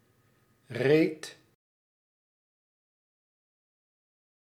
Ääntäminen
US : IPA : [ɹoʊd]